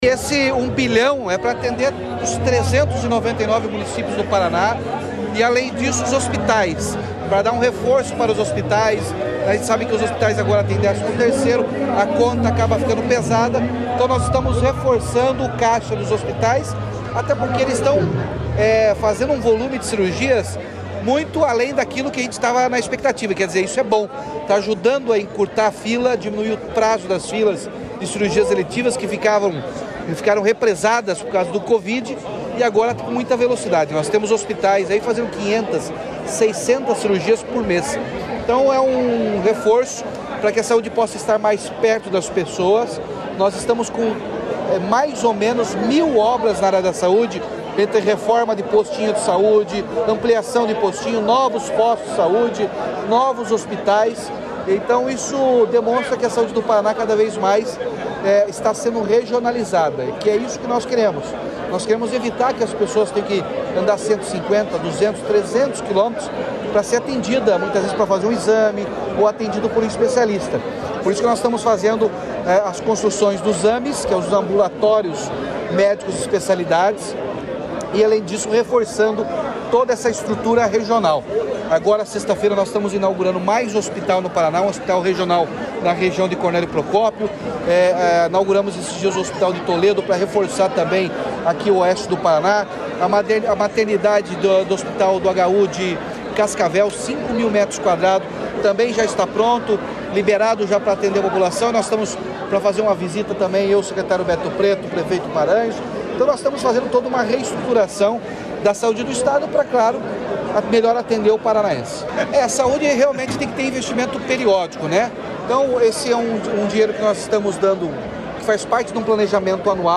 Sonora do governador Ratinho Junior sobre o anúncio de R$ 1 bilhão para fortalecer a saúde no Paraná